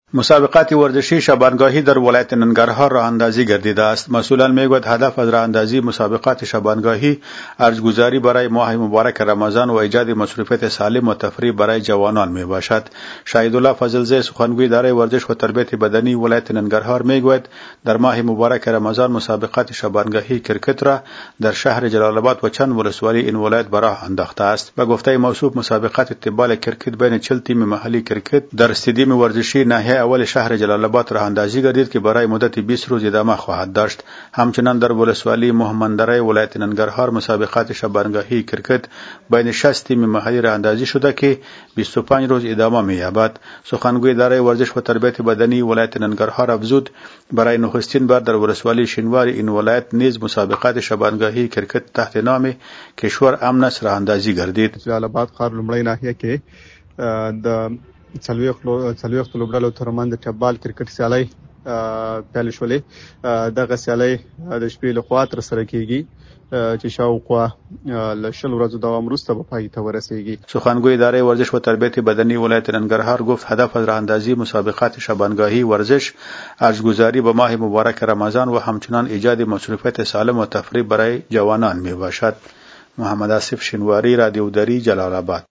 خبر / ورزشی